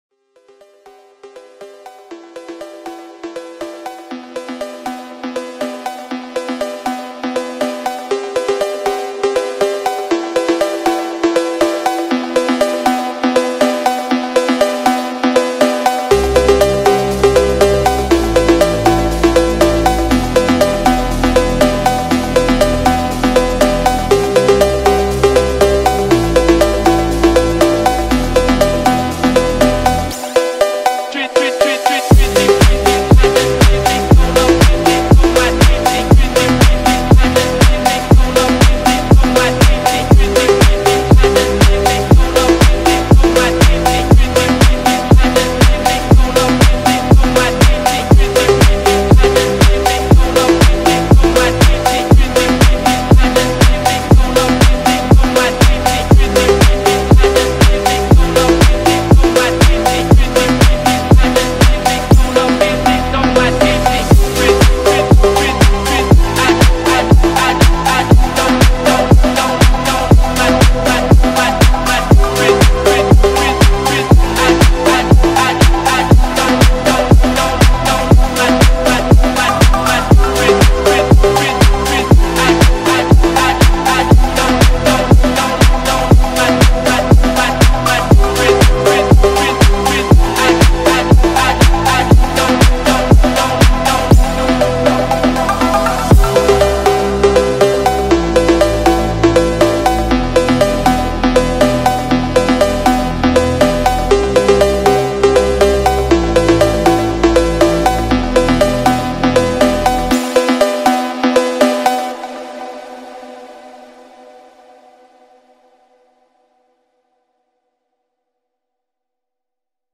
فانک
ماشینی